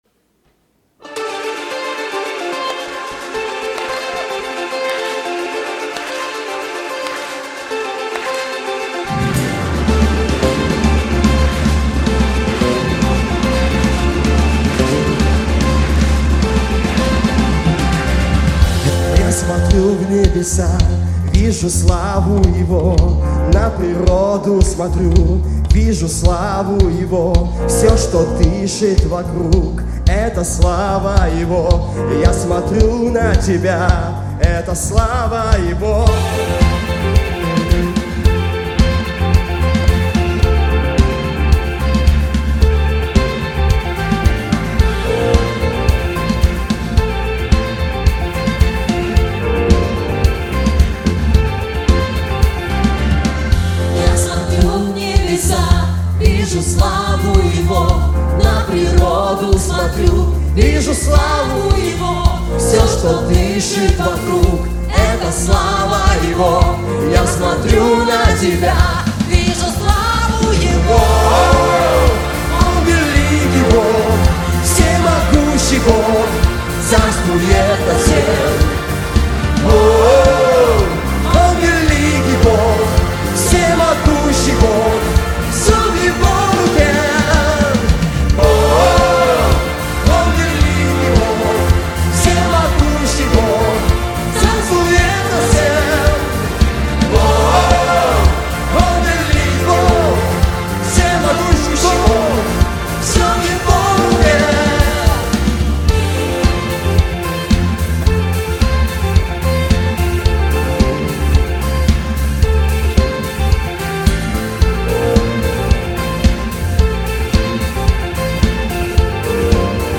песня
496 просмотров 491 прослушиваний 27 скачиваний BPM: 110